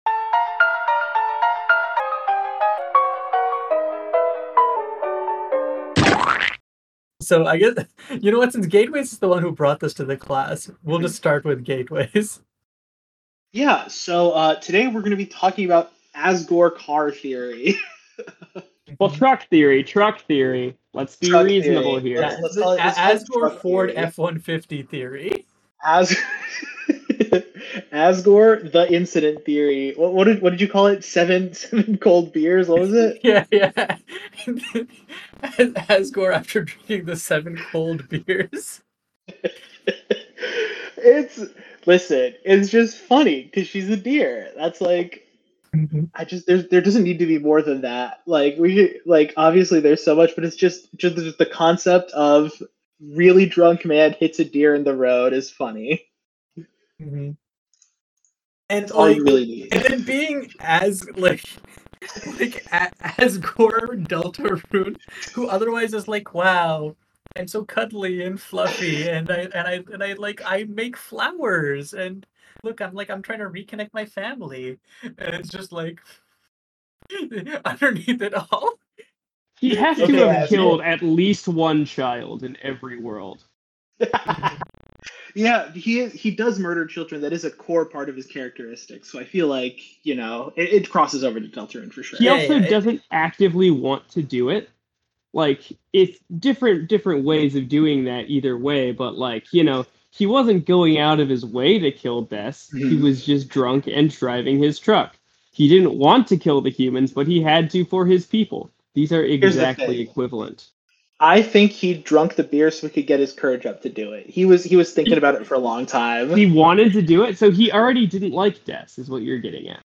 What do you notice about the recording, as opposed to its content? We apologize for that, it'll be resolved in future episodes. We hope it's still listenable.